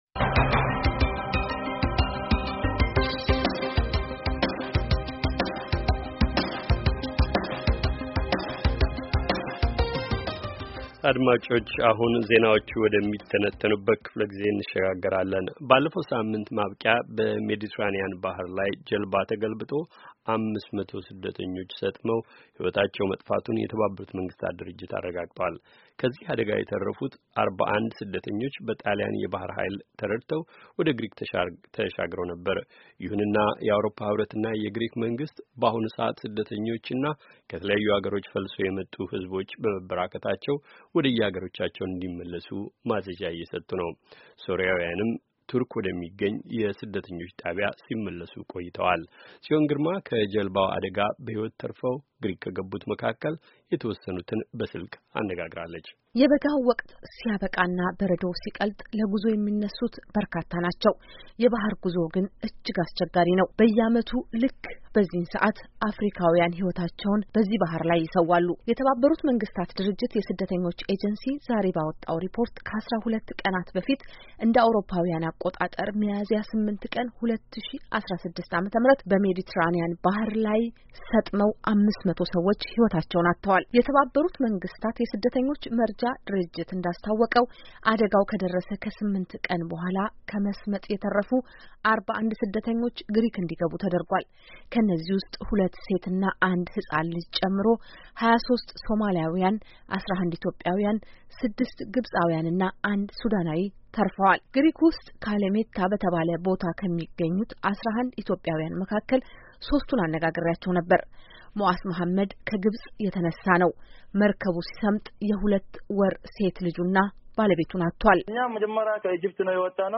ከመስመጥ የተርፉት ኢትዮጵያውያን 11 ብቻ ናቸው፤ ሦስቱን አነጋግረናቸዋል
ሚስትና ልጁን ያጣ፣እናትና ወንድሞቹን ውሃ የበላበት፣እህቱን እና 28 ጓደኞቹን ያጣ ናቸው። ስላሳለፉት ጉዞ አስቸጋሪነትና ለመሄድ ስለተዘጋጁ ስደተኖች የሚነግረን ወጣትም ከግብጽ አነጋግረናል።